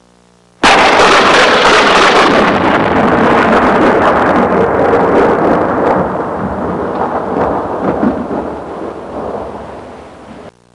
Sharp Thunder Sound Effect
Download a high-quality sharp thunder sound effect.
sharp-thunder.mp3